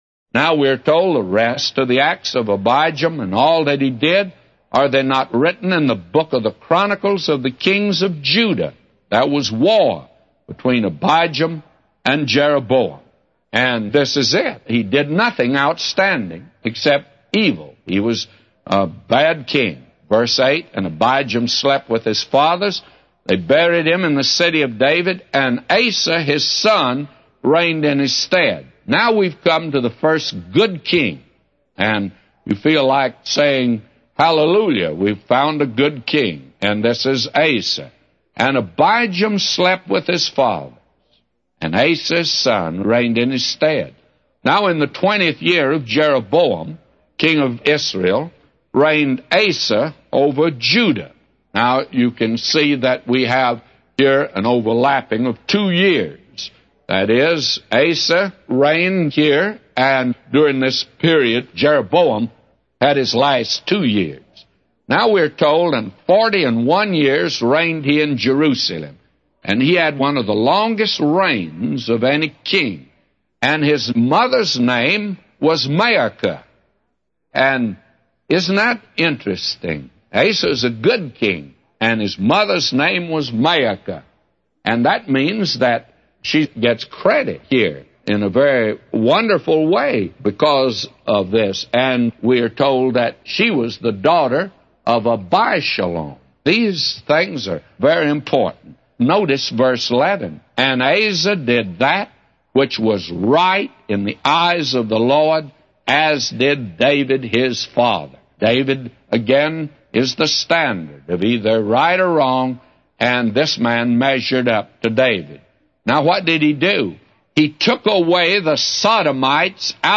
A Commentary By J Vernon MCgee For 1 Kings 15:7-999